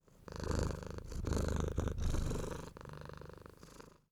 Cat Purr.mp3